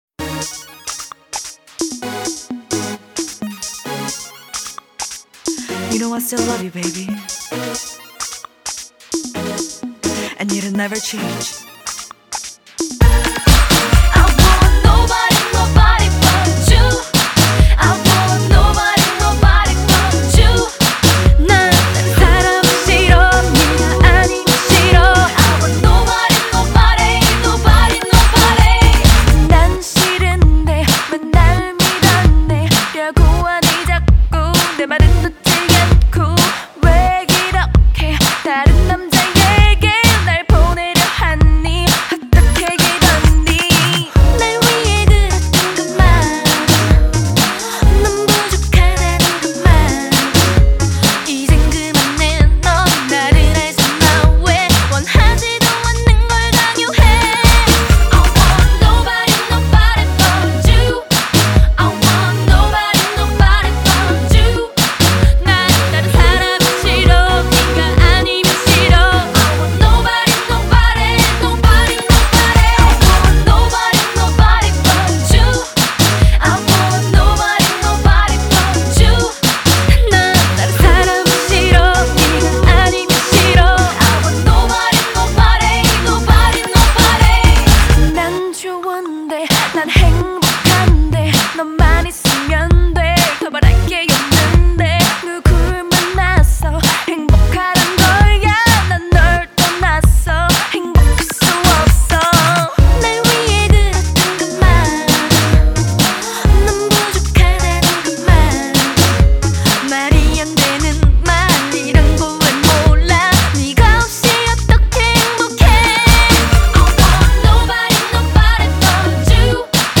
注入流行元素，呈现出不一样的感觉。
韩国组合歌手